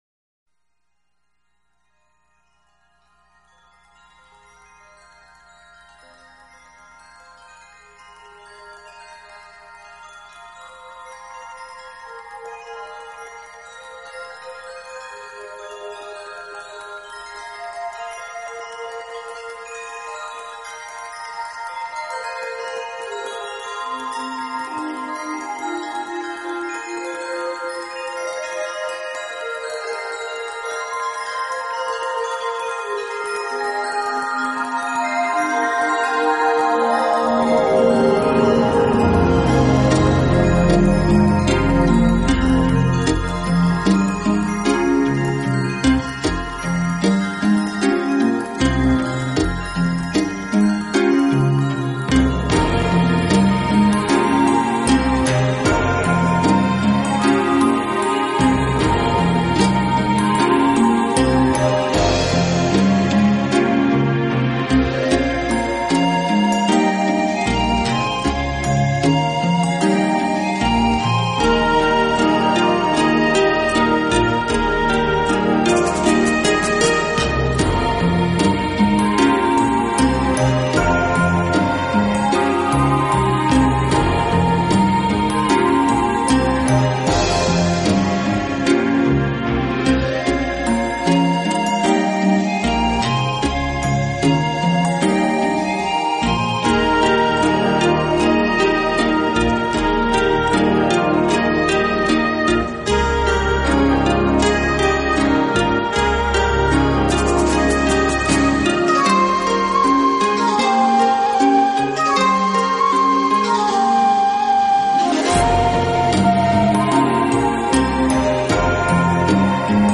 带给我们的欢乐，他的作品悠闲富有情趣，没有那股纵欲过度、自我毁灭的倾向。